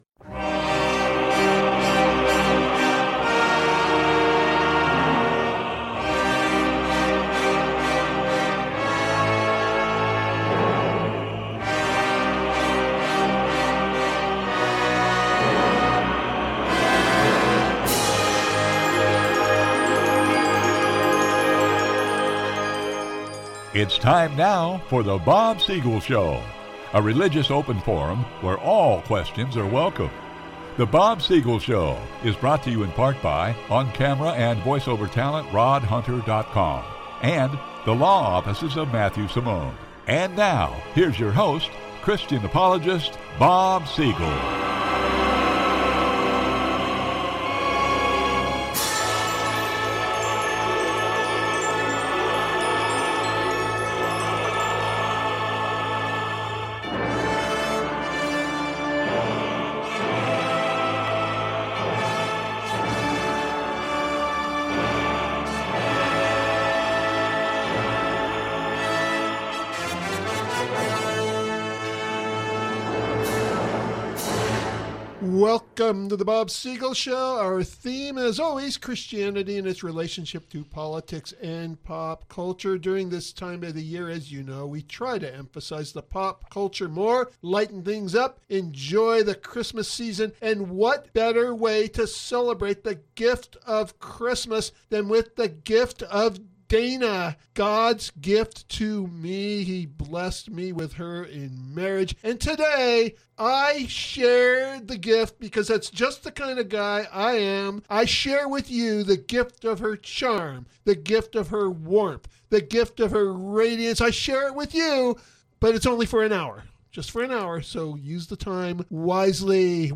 Repeat program